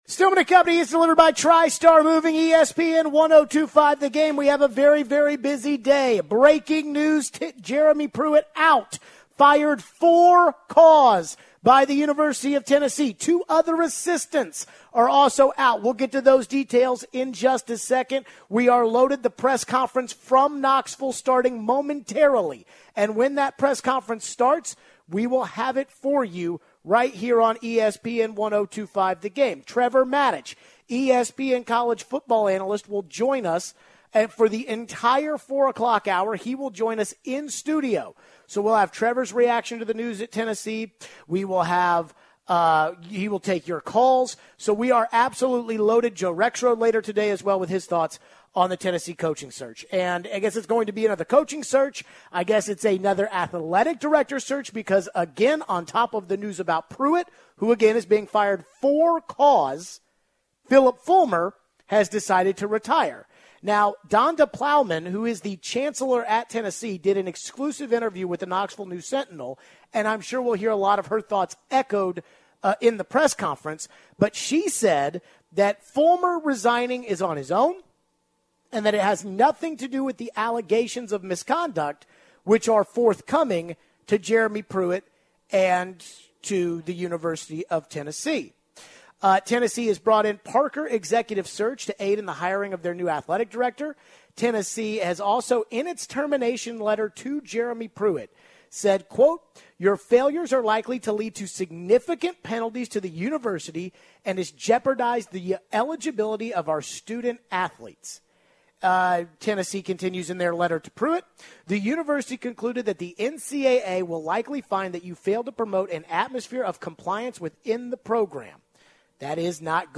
Headliner Embed Embed code See more options Share Facebook X Subscribe Full reaction to the news out of Knoxville and the firing of Jeremy Pruitt. Hear the press conference from today with Fulmer and The Chancellor and President.